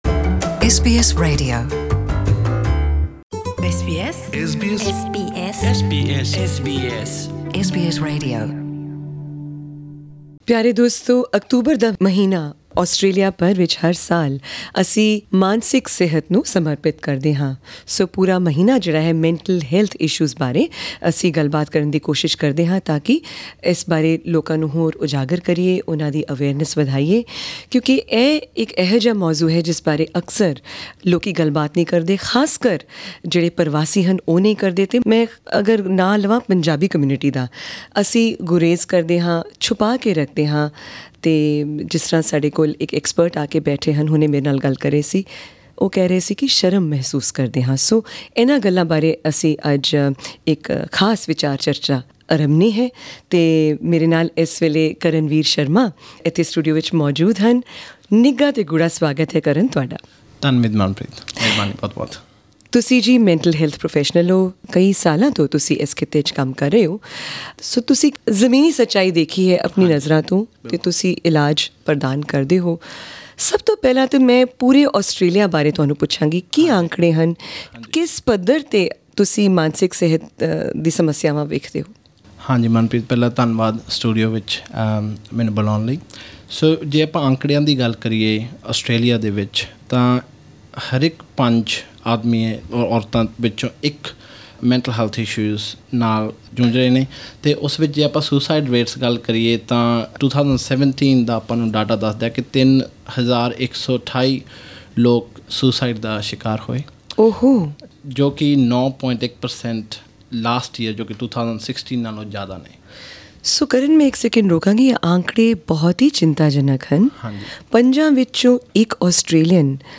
at SBS's Melbourne studio